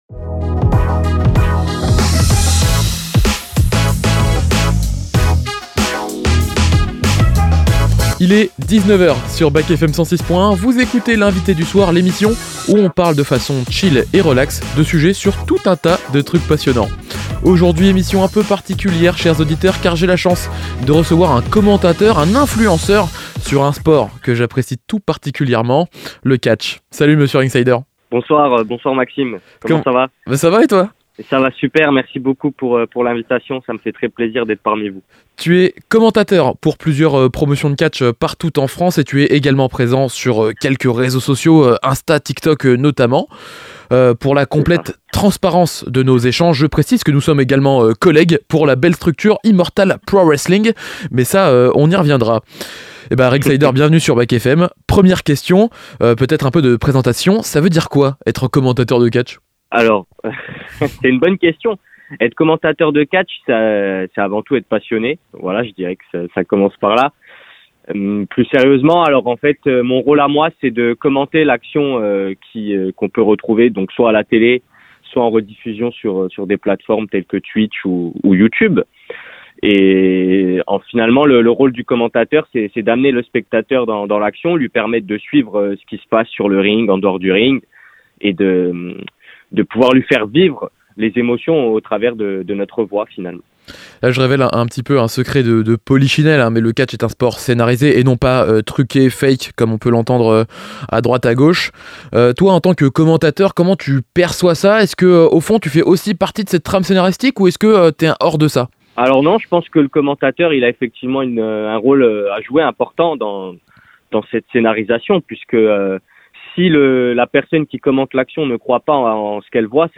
commentateur de catch en podcast